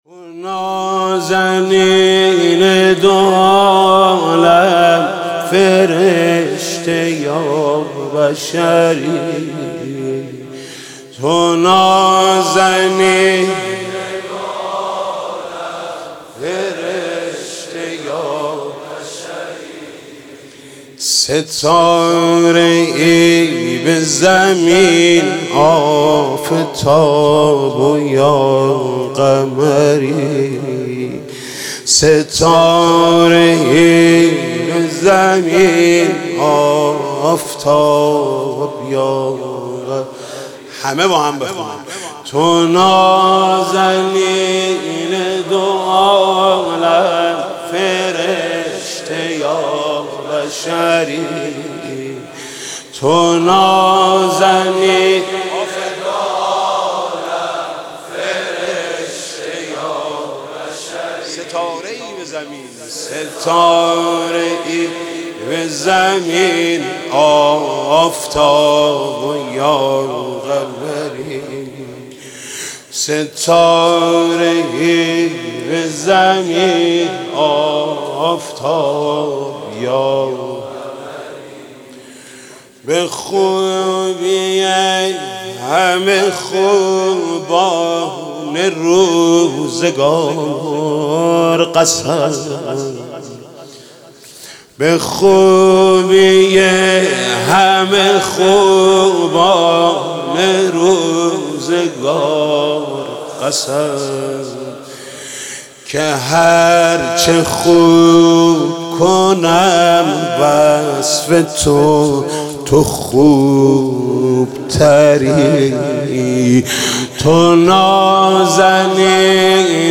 مدح: تو نازنین دو عالم فرشته یا بشری
مدح: تو نازنین دو عالم فرشته یا بشری خطیب: حاج محمود کریمی مدت زمان: 00:06:17